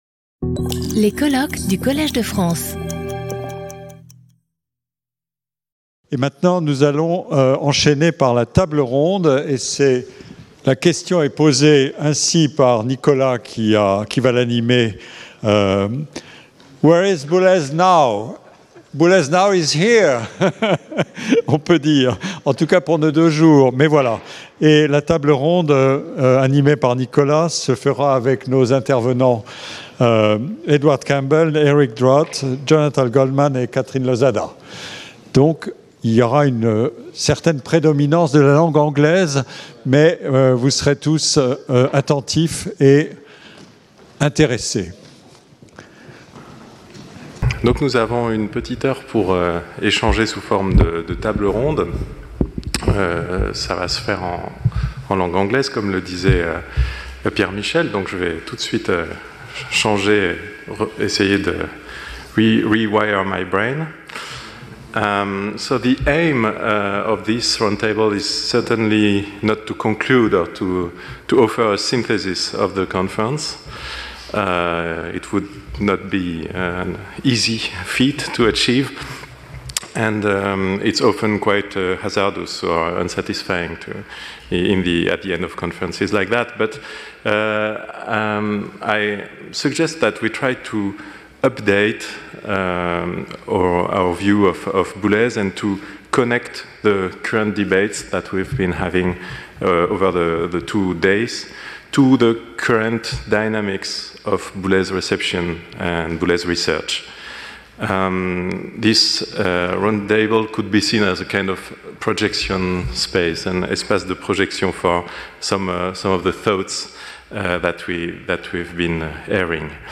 Table-ronde conclusive « Where is Boulez now? » | Collège de France